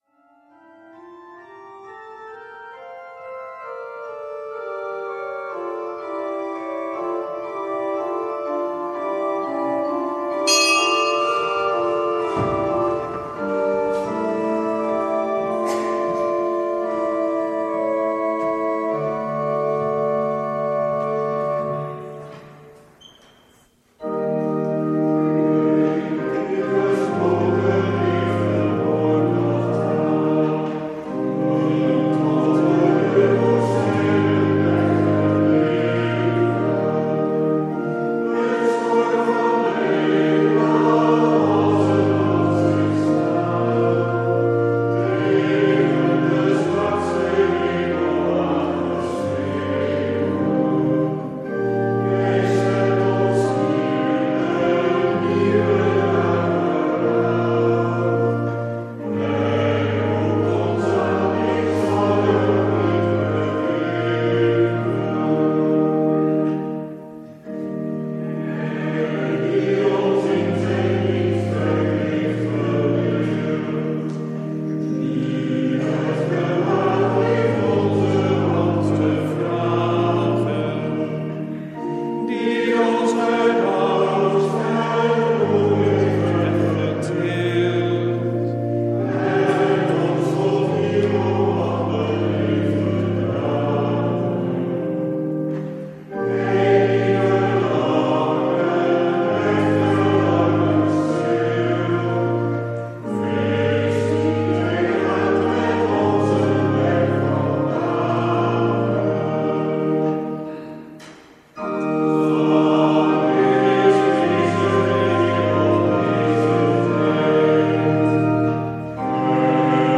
Lezingen
Eucharistieviering beluisteren vanuit de H. Jozef te Wassenaar (MP3)